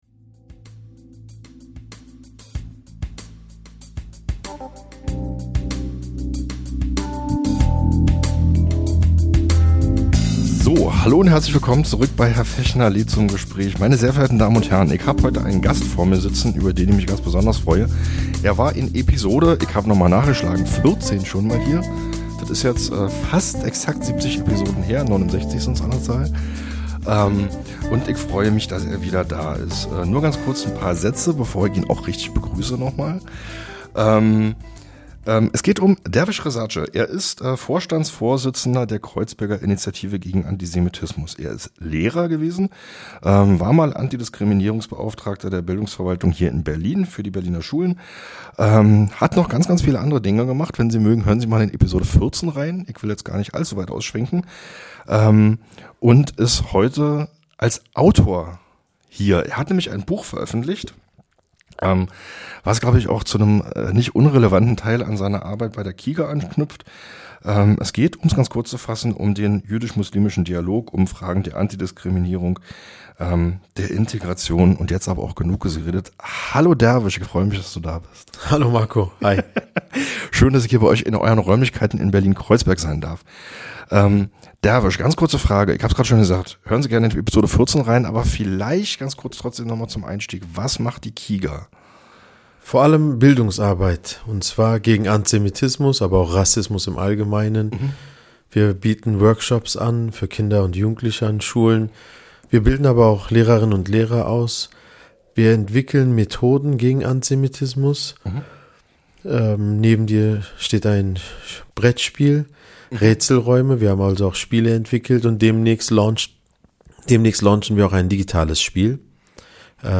Es war ein persönliches Gespräch über Antisemitismus, seinen Blick auf das Zusammenleben in dieser Stadt, über das Anrühren von Joghurt und die Frage "Was, wenn es gelingt?"